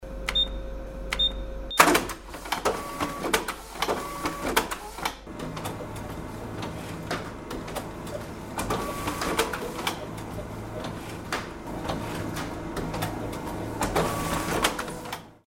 Звуки ксерокса
На этой странице собраны звуки ксерокса: от монотонного гула до ритмичного шума печати.
Включение и запуск печати ксерокса